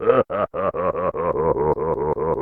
Bleak's laugh.
Bleak_SFX_1.oga